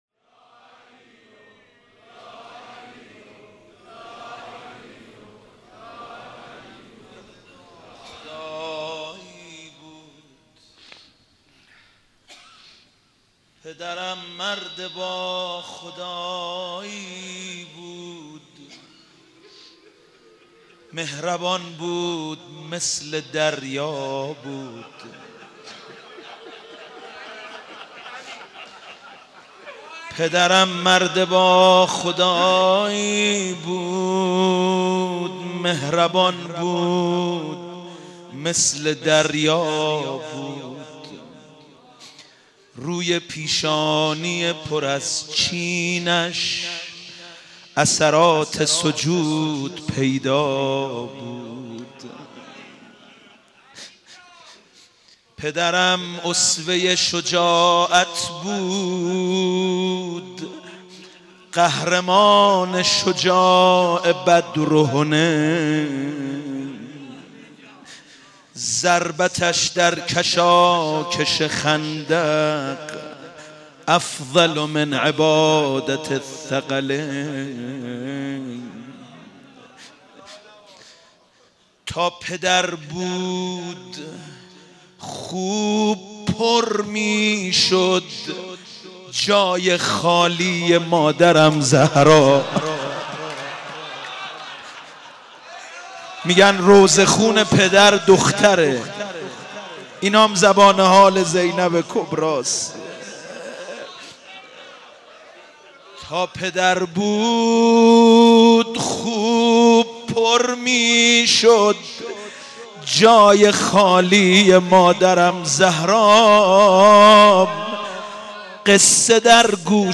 روضه
روضه - روز ٢١ رمضان.mp3